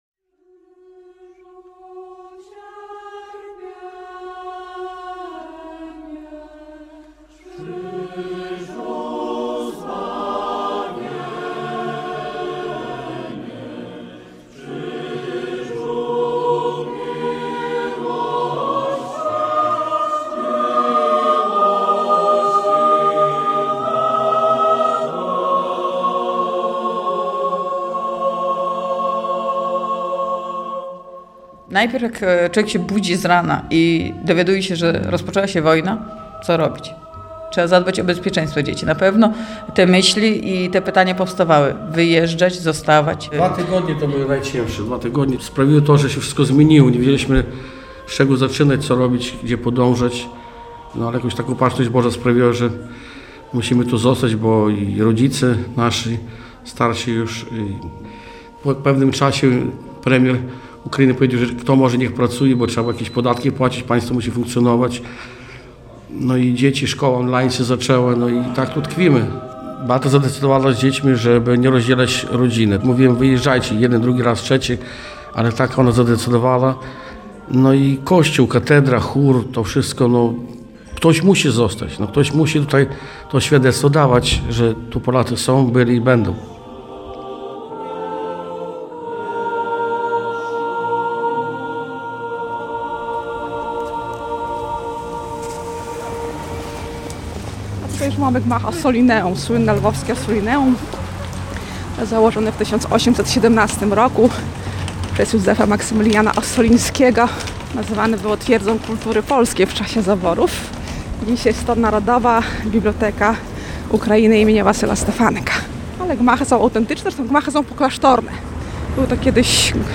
W atmosferze przejmującego chóralnego śpiewu o życiu i trudnych wyborach czasu wojny opowiadają